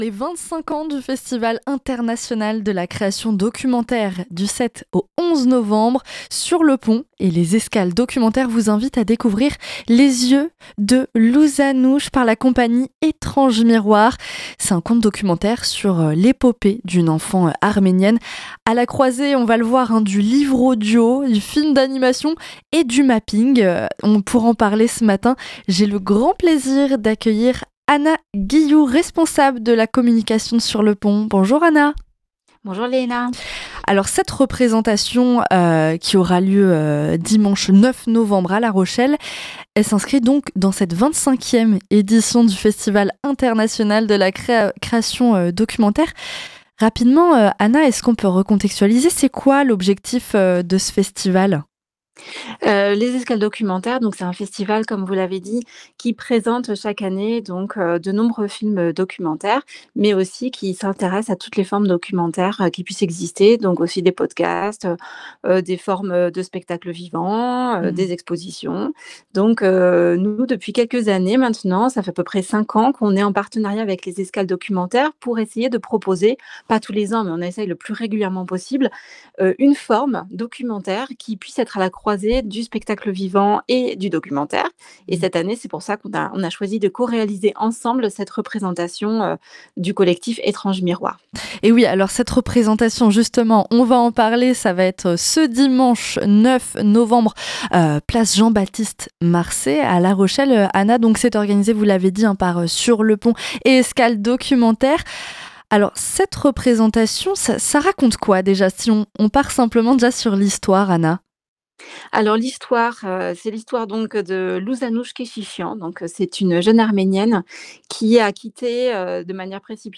L’interview est à retrouver ci-dessous.